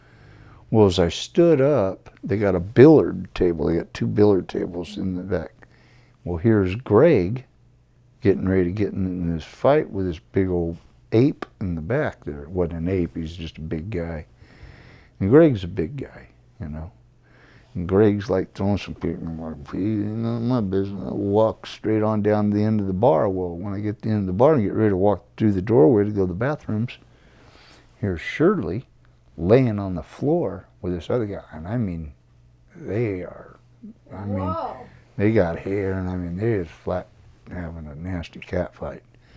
Barfight
BarFight.wav